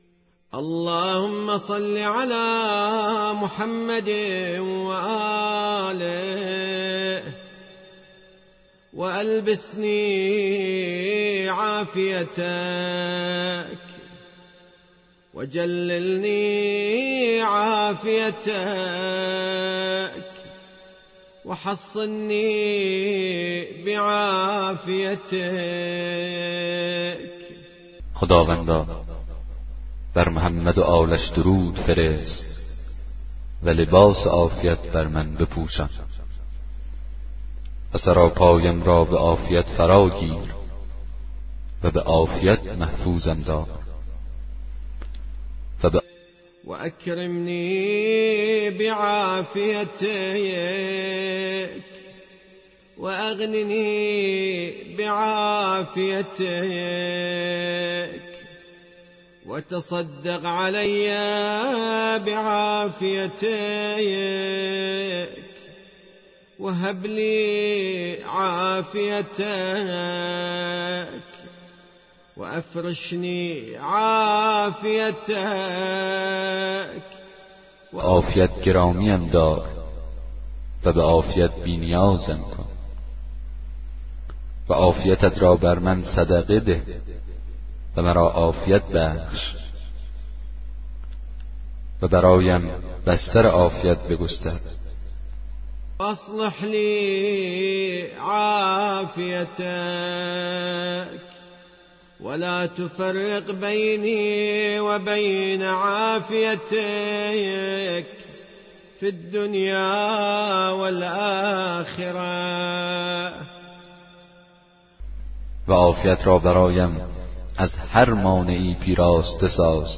کتاب صوتی دعای 23 صحیفه سجادیه